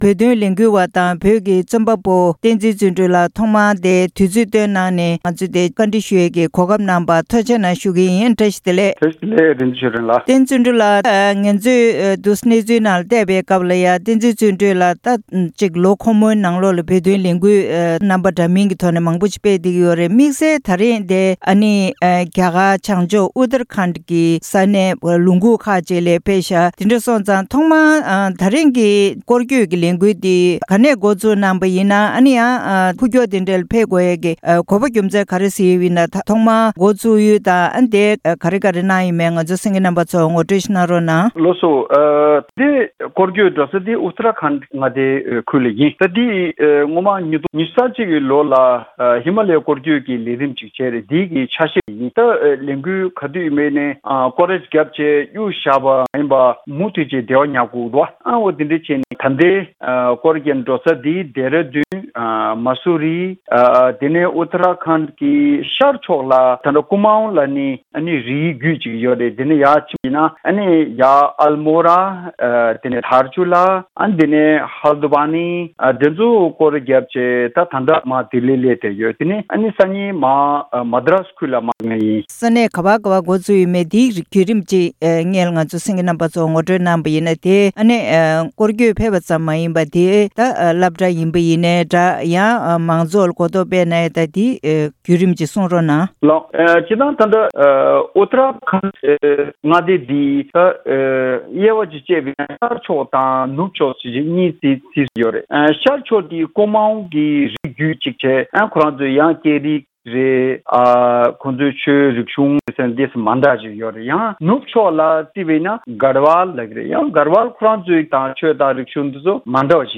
གནས་འདྲིའི་ལེ་ཚན་ནང་།